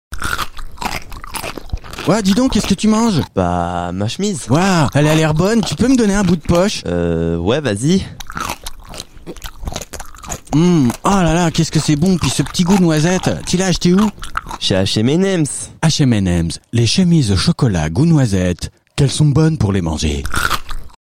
Fausses Pubs RADAR parodies publicités Fausses pubs